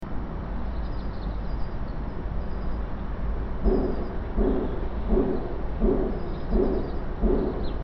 Figure 4: Analysis of impulsivity of blanking press